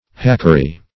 Search Result for " hackery" : The Collaborative International Dictionary of English v.0.48: hackery \hack"er*y\ (h[a^]k"[~e]r*[y^]), n. [Hind. chhakr[=a].]